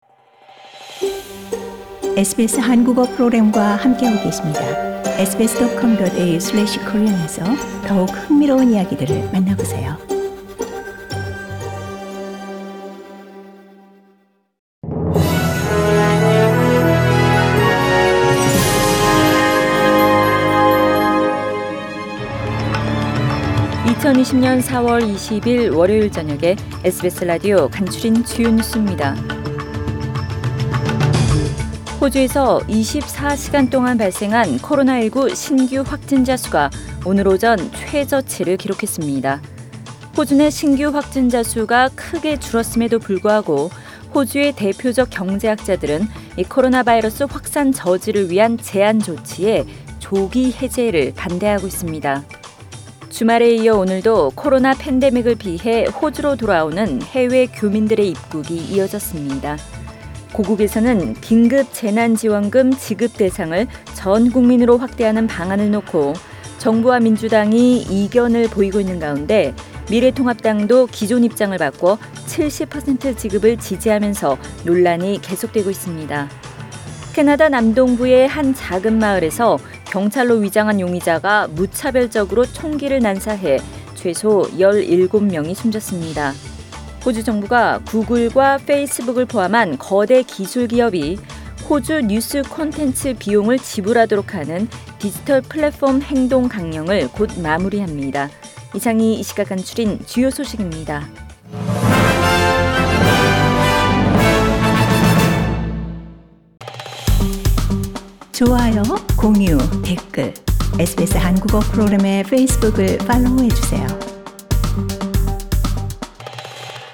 2020년 4월 20일 월요일 저녁의 SBS Radio 한국어 뉴스 간추린 주요 소식을 팟 캐스트를 통해 접하시기 바랍니다.